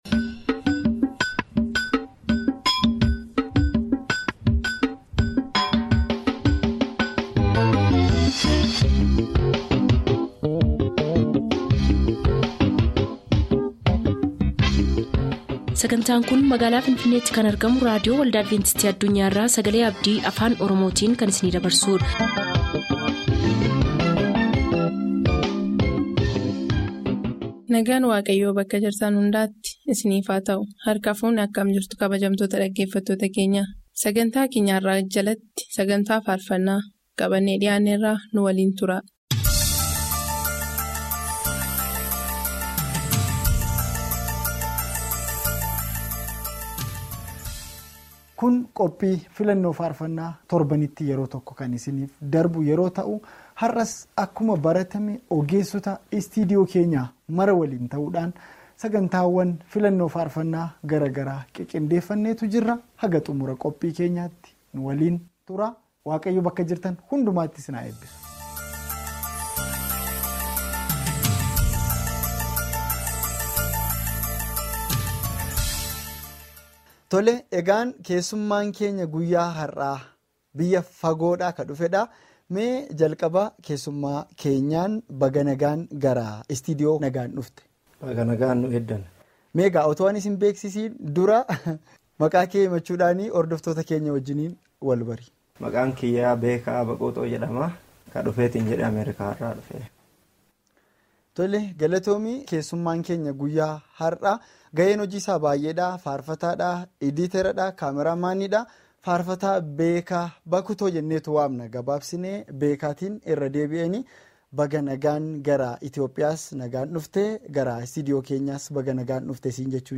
SAGANTAA FAARFANNAA SAGALEE ABDII AFAAN OROMOO. SONG PROGRAME FROM ADVENTIST WORLD RADIO OROMO